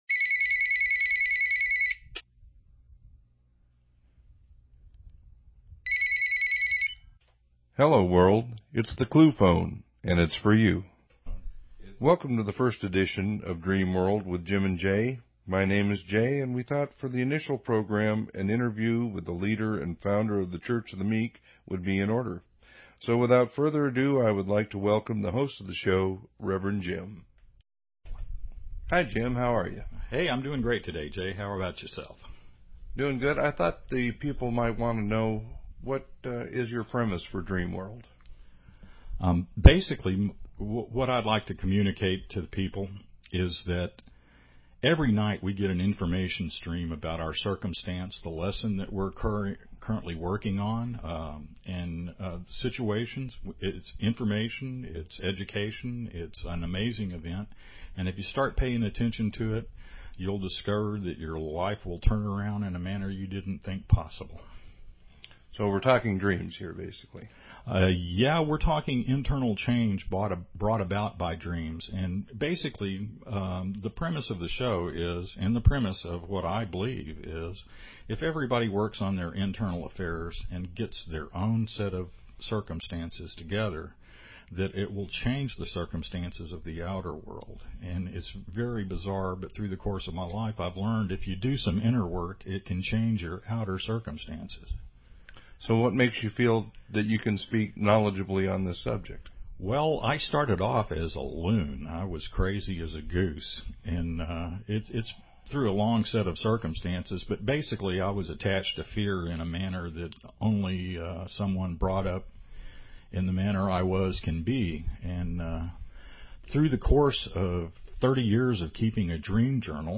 Talk Show Episode, Audio Podcast, Dream_World and Courtesy of BBS Radio on , show guests , about , categorized as